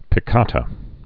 (pĭ-kätə)